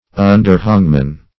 Underhangman \Un"der*hang`man\, n.